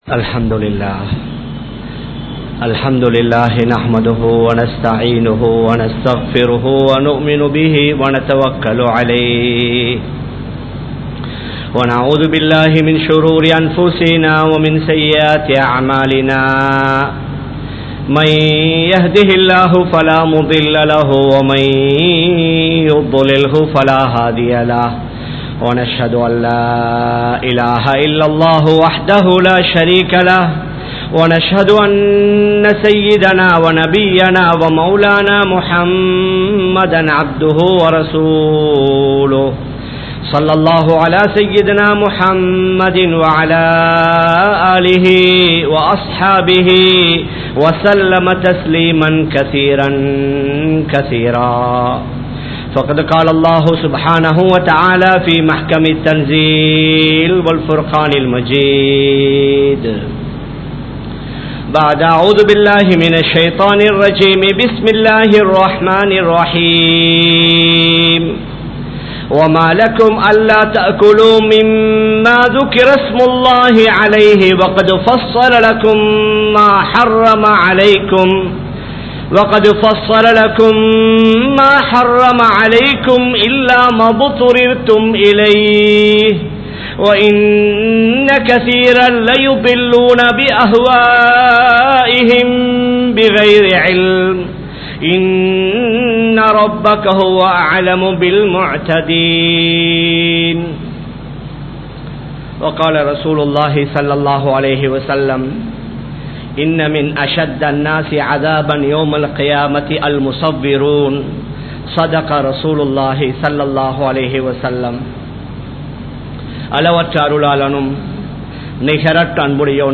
Musicum Photovum Indraya Samoohamum (இசையும் புகைப்படமும் இன்றைய சமூகமும்) | Audio Bayans | All Ceylon Muslim Youth Community | Addalaichenai
Kollupitty Jumua Masjith